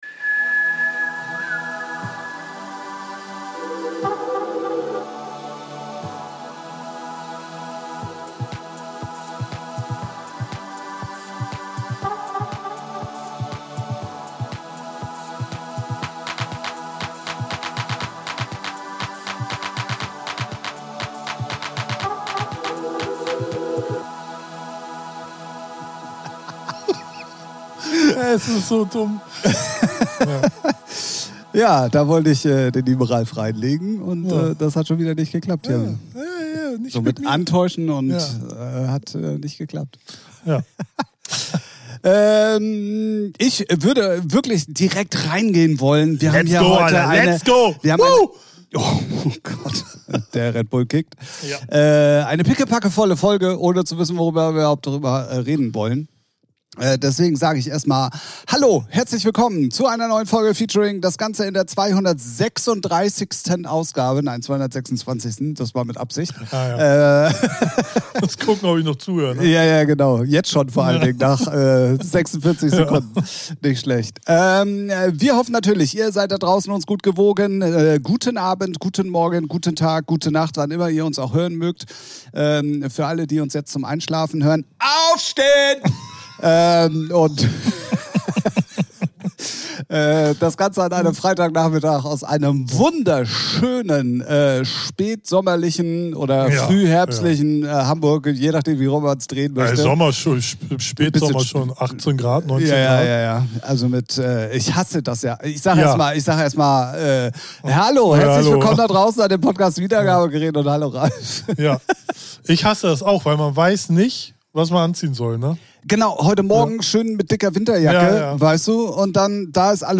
Rode Procaster Quality Dynamic Mikrofon